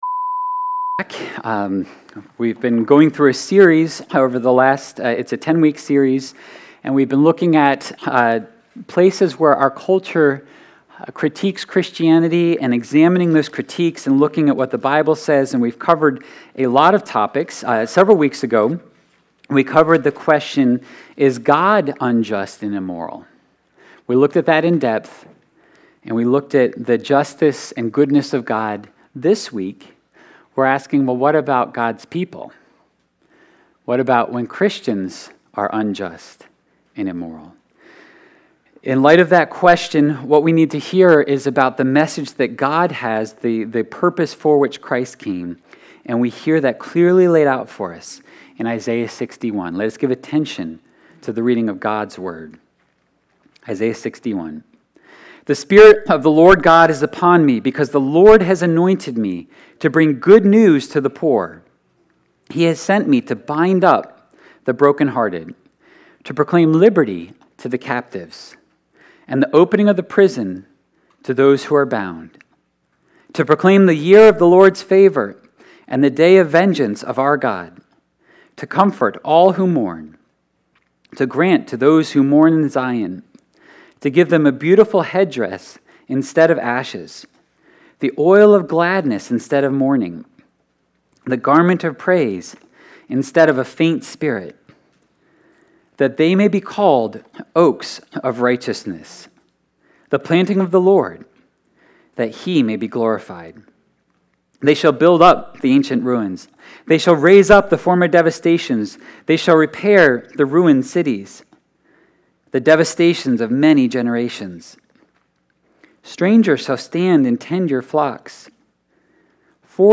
7.17.22-sermon-audio.mp3